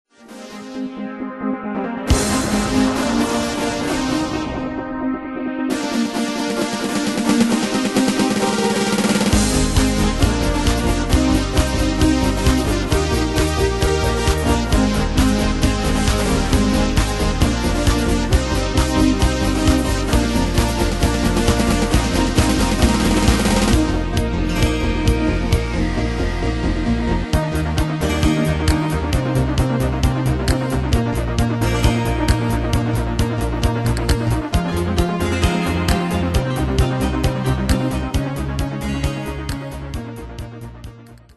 Style: Dance Année/Year: 2002 Tempo: 133 Durée/Time: 4.12
Danse/Dance: Dance Cat Id.
Pro Backing Tracks